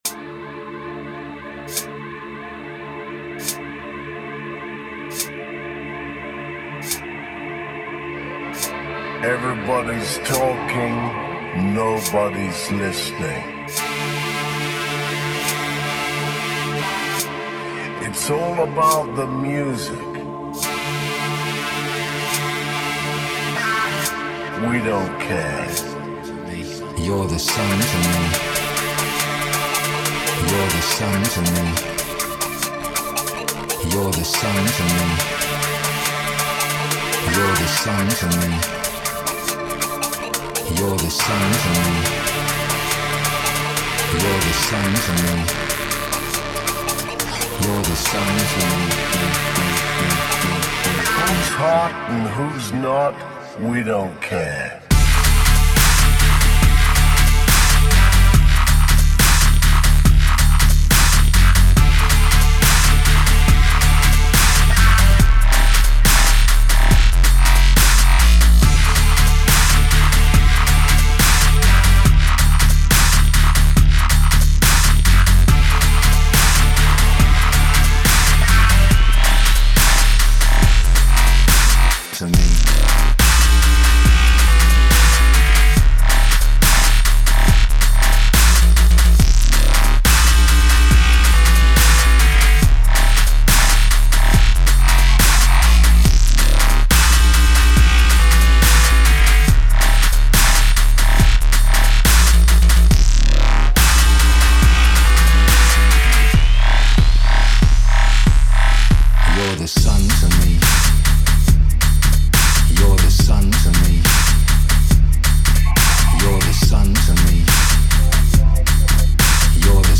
Категория: Dub step